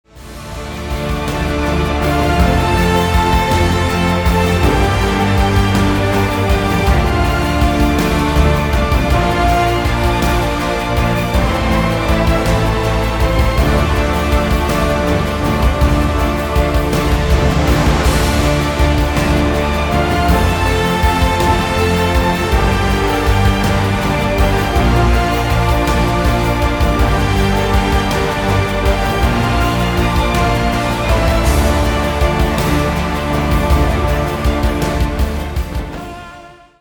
Стиль: epic music Ура!